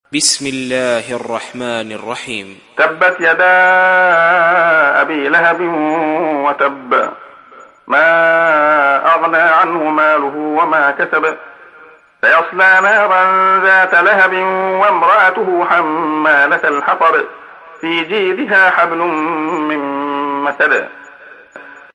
تحميل سورة المسد mp3 بصوت عبد الله خياط برواية حفص عن عاصم, تحميل استماع القرآن الكريم على الجوال mp3 كاملا بروابط مباشرة وسريعة